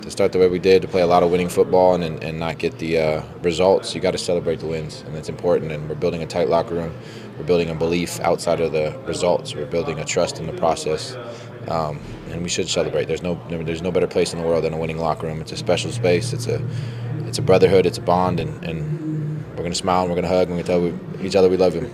QB McLeod Bethel-Thompson has had an up and down season with the Elks, being benched for Tre Ford earlier in the season, now starting again for the injured Ford, spoke to media following the win about the teams resilience this season.